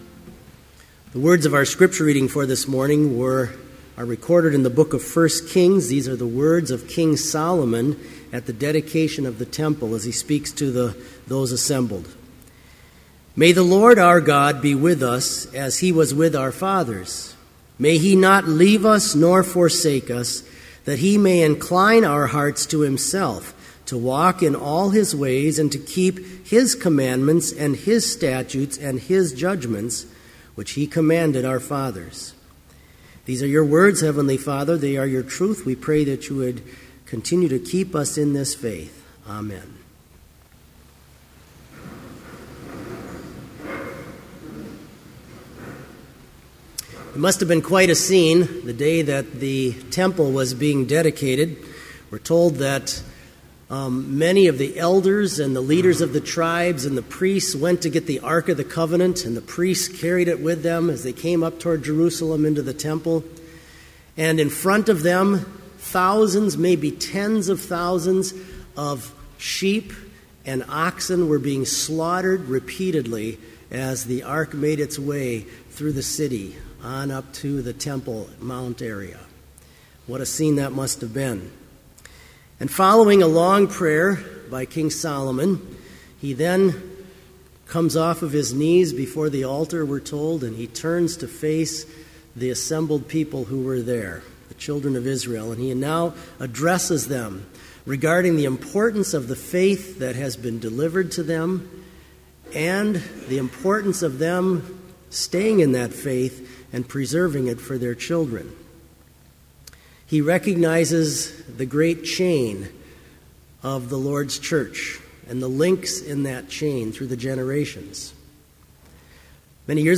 Complete Service
• Prelude
• Hymn 2, Come, Holy Ghost, God and Lord
• Homily
This Chapel Service was held in Trinity Chapel at Bethany Lutheran College on Monday, October 29, 2012, at 10 a.m. Page and hymn numbers are from the Evangelical Lutheran Hymnary.